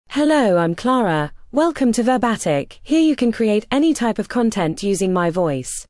FemaleEnglish (United Kingdom)
ClaraFemale English AI voice
Clara is a female AI voice for English (United Kingdom).
Voice sample
Female
English (United Kingdom)